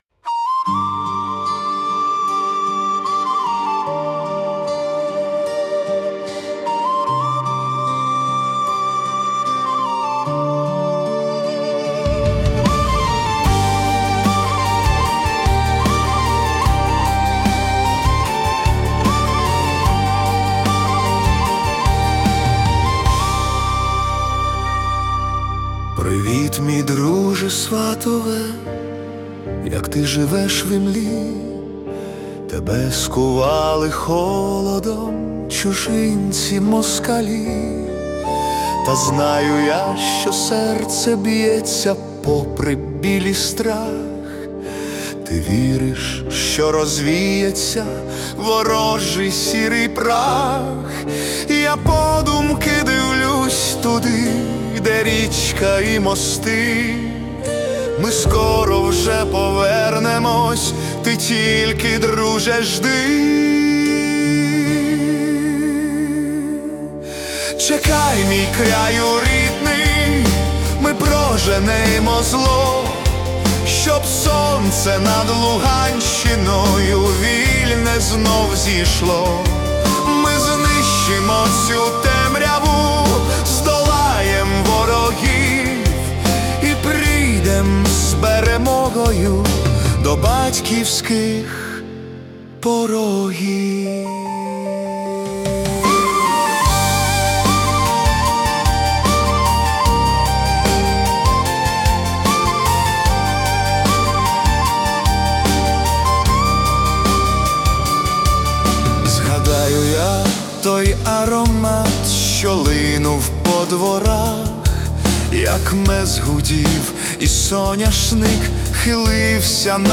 Ukrainian Ballad / Whistle